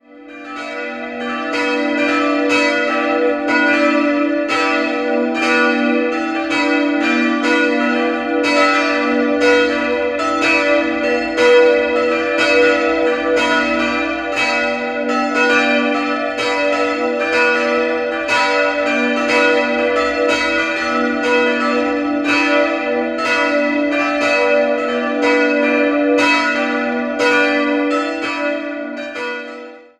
Im Jahr 1978 wurde die evangelische Markuskirche im Ortsteil Altenberg eingeweiht. 3-stimmiges TeDeum-Geläut: h'-d''-e'' Die Glocken wurden im Jahr 1975 von der Karlsruher Glockengießerei gegossen.